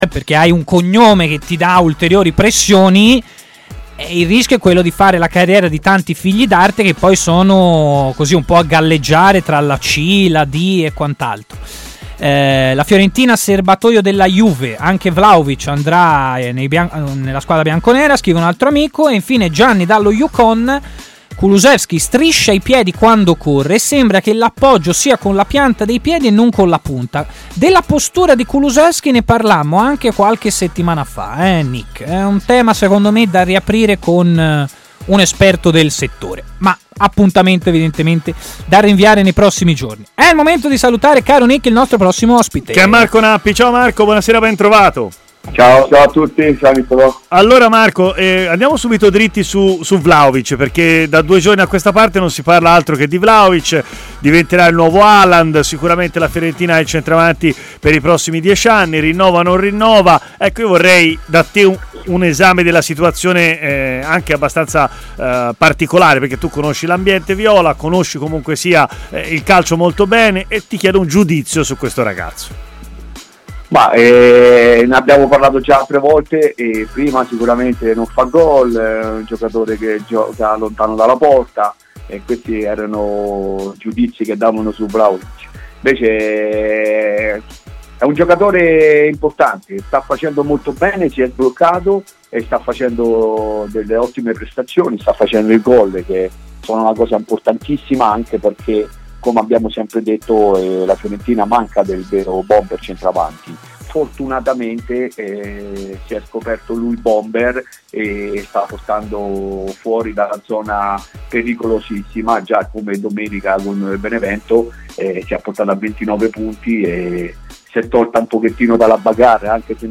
L'ex attaccante
intervenuto in diretta a TMW Radio, nel corso della trasmissione Stadio Aperto